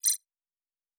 pgs/Assets/Audio/Sci-Fi Sounds/Interface/Digital Click 04.wav at master
Digital Click 04.wav